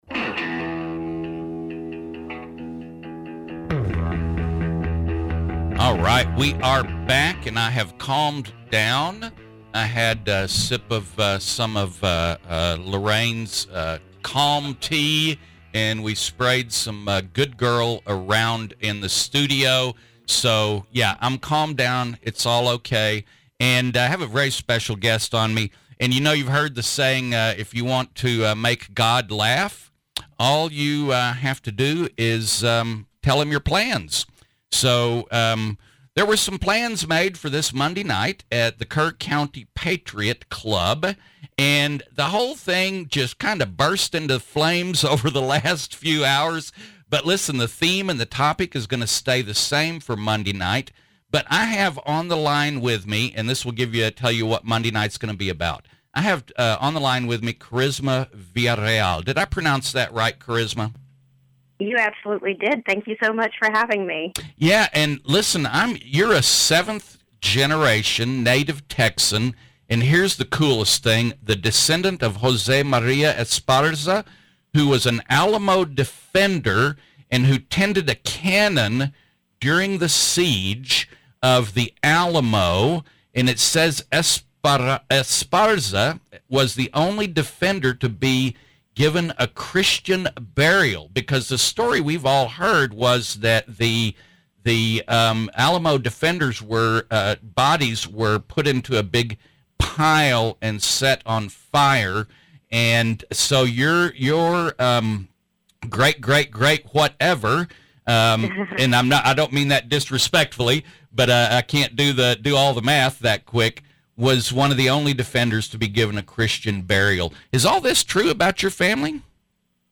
Charisma Interview Kerrville